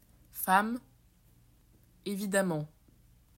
You will hear a series of four words. Some will use the [a] sound, while others will not.